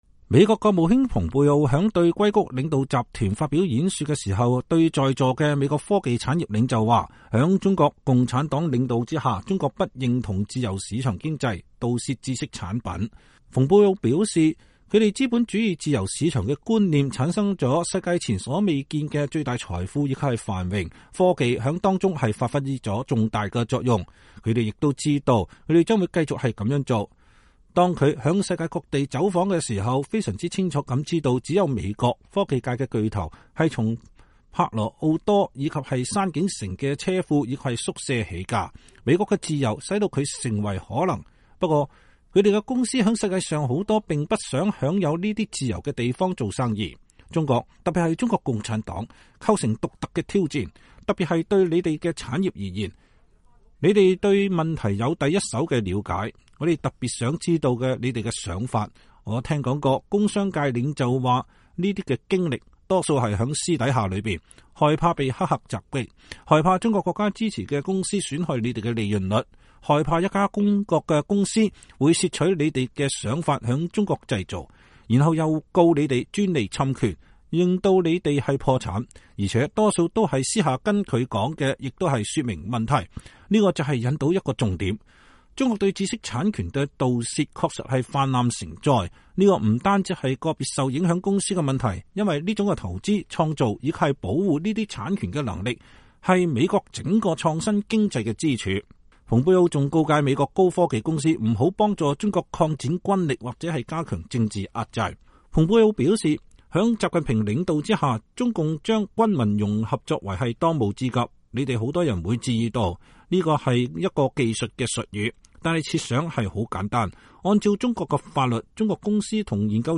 美國國務卿蓬佩奧在加州矽谷領導集團發表講話。(1月13日)